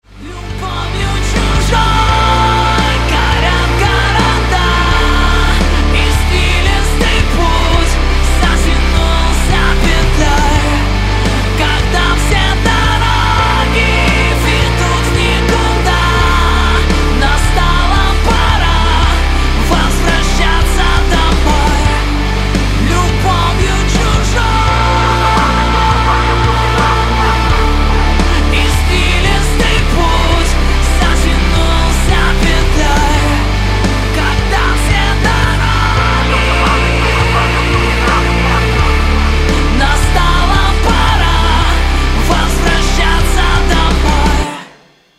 • Качество: 128, Stereo
громкие
Alternative Rock
русский рок
кавер